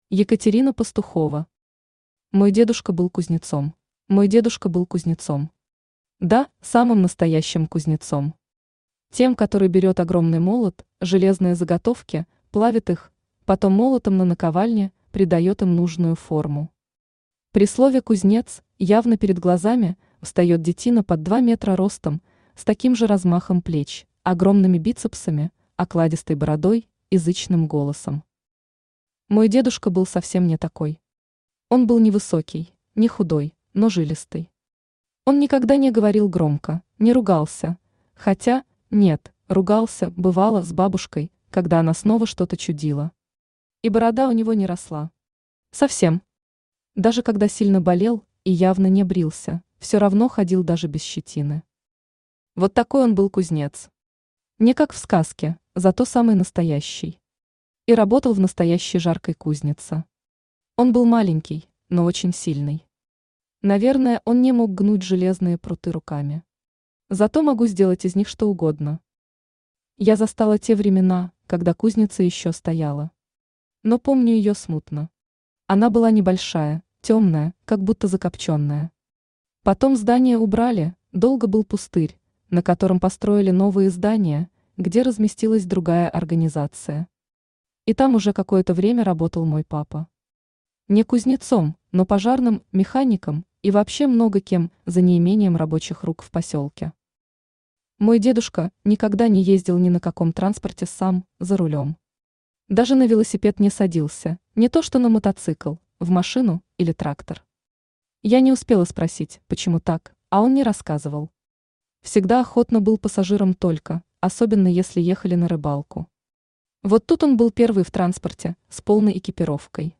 Аудиокнига Мой дедушка был кузнецом | Библиотека аудиокниг
Aудиокнига Мой дедушка был кузнецом Автор Екатерина Евгеньевна Пастухова Читает аудиокнигу Авточтец ЛитРес.